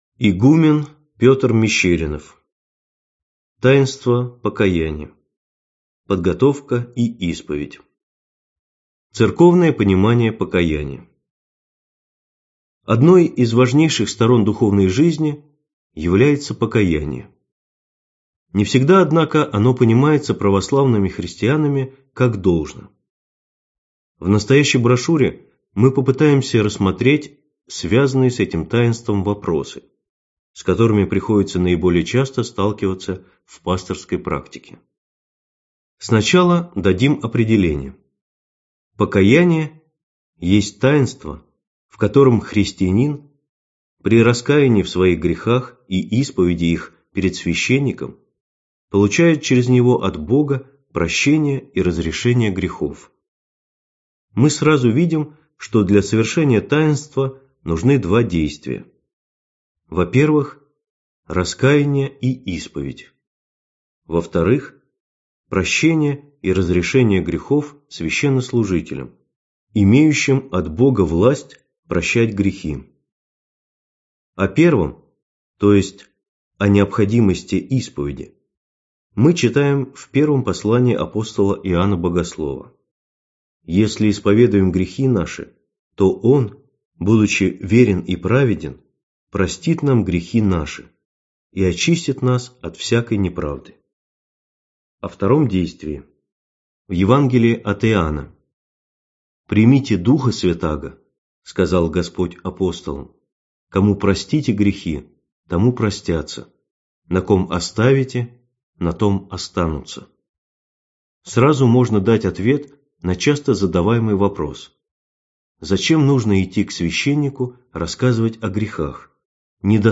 Аудиокнига Таинство покаяния: подготовка и исповедь | Библиотека аудиокниг